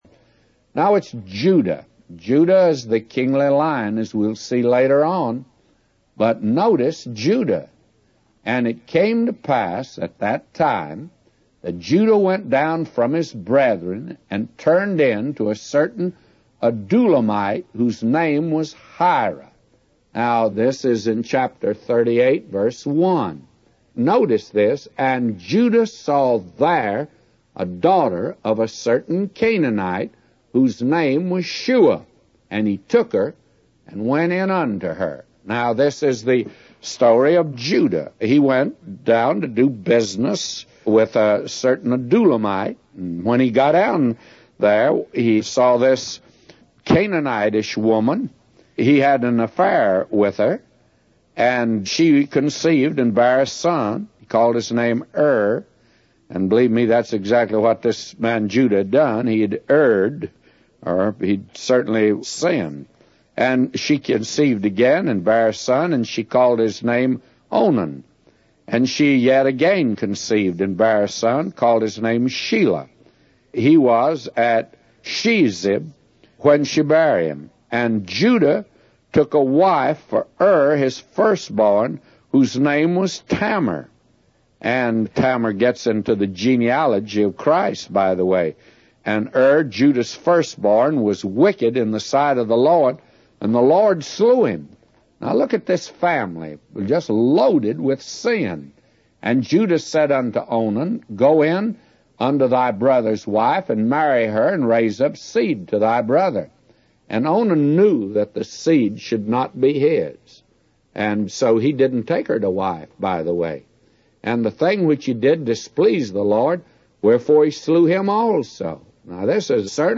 A Commentary By J Vernon MCgee For Genesis 38:1-999